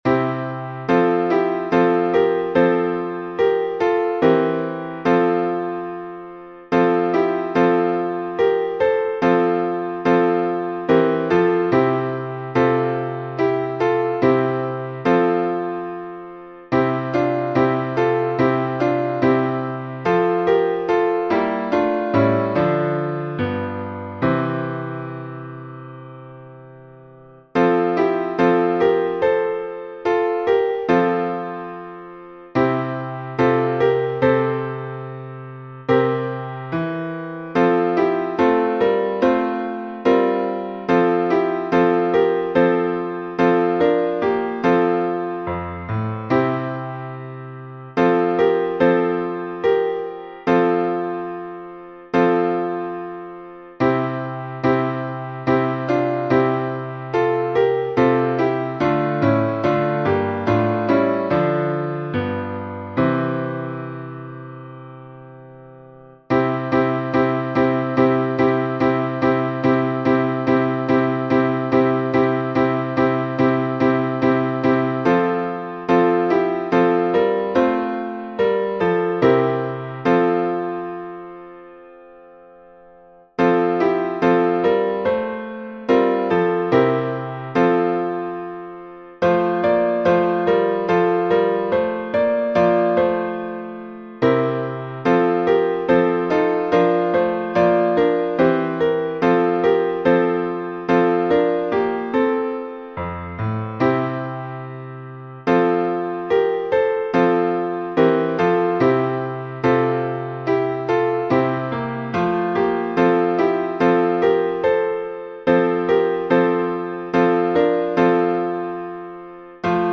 Глас 1 — Антифоны степенные